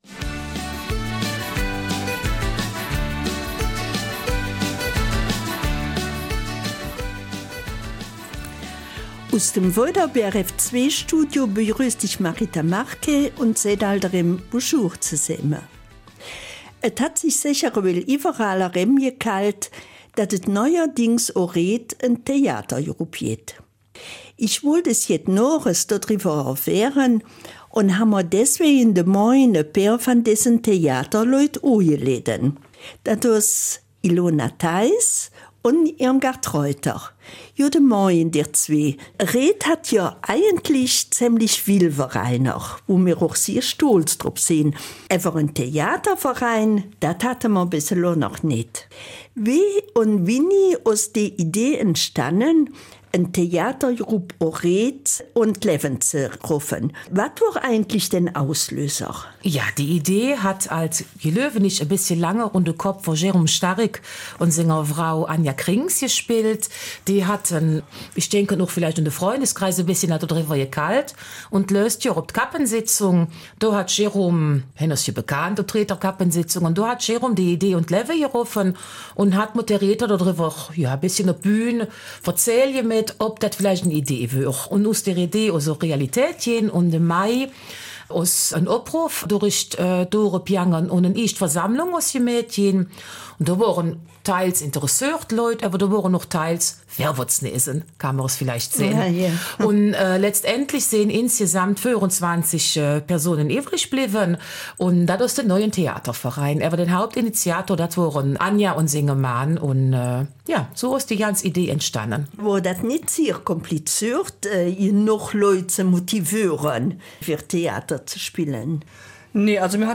im Studio des BRF2